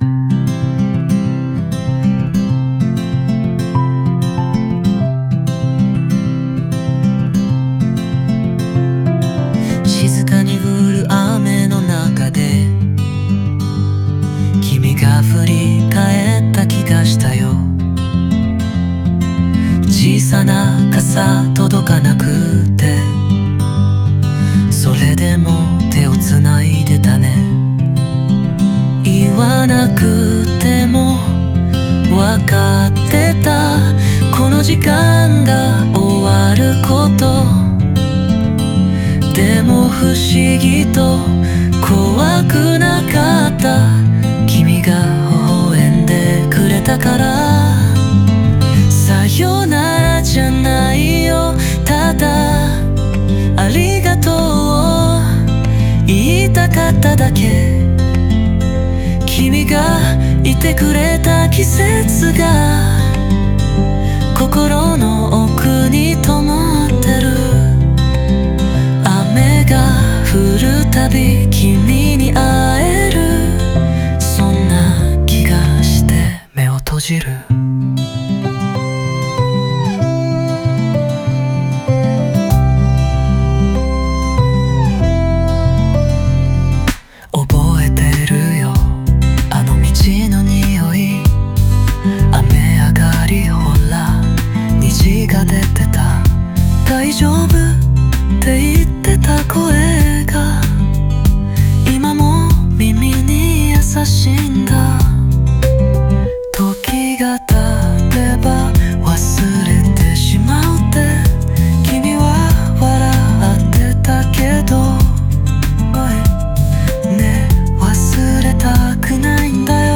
語りかけるような言葉で、失ったはずのぬくもりを心の中に灯し続ける姿を描きます。